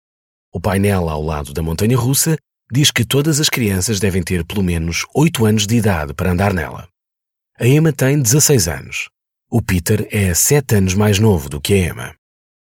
PT EU LM EL 01 eLearning/Training Male Portuguese - Portugal